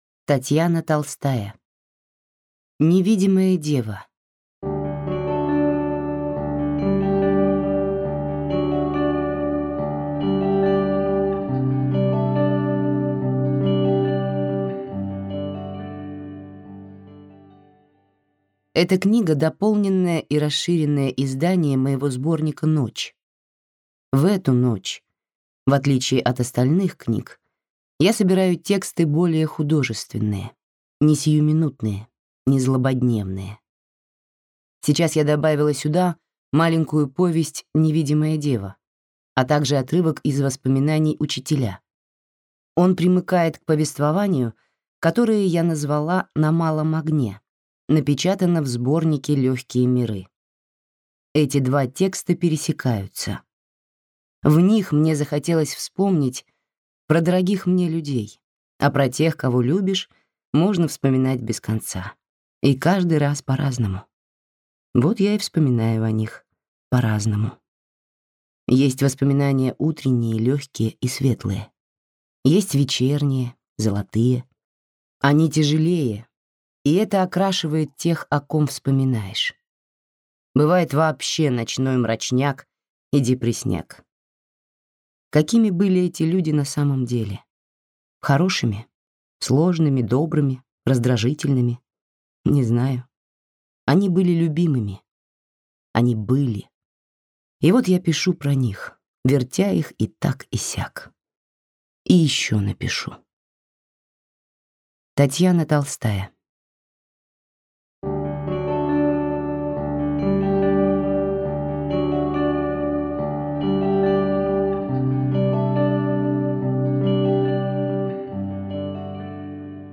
Аудиокнига Невидимая дева | Библиотека аудиокниг